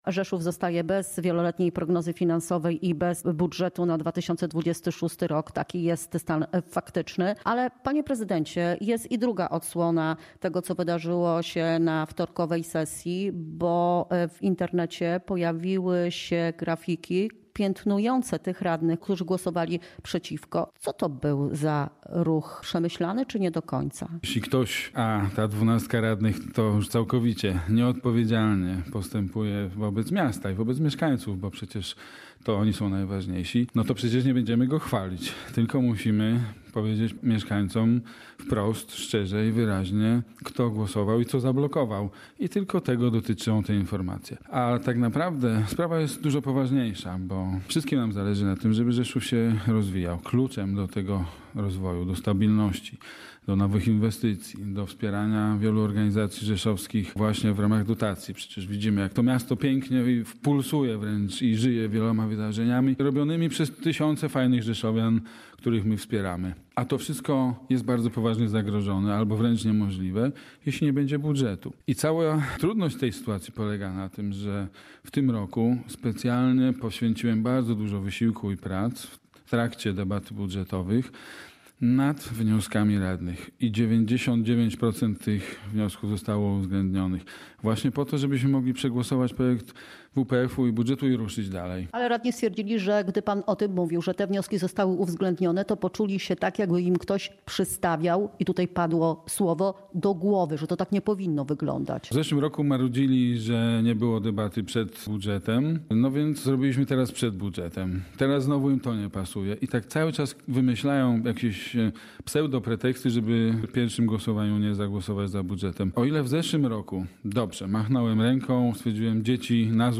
Jeśli radni Prawa i Sprawiedliwości oraz Razem dla Rzeszowa poprą Wieloletnią Prognozę Finansową i budżet na 2026 rok, sesja może odbyć się jeszcze przed świętami – poinformował na antenie Radia Rzeszów prezydent Konrad Fijołek.